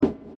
Gently roll off a lot of the highs, probably a little more than you might think is necessary.